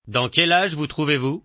AoE2 Taunt FR 42 - what age are you in